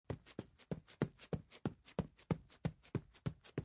runningAudio.mp3